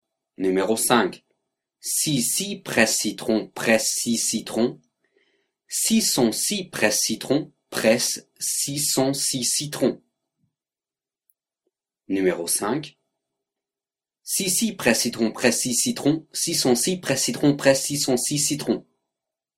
05 Virelangue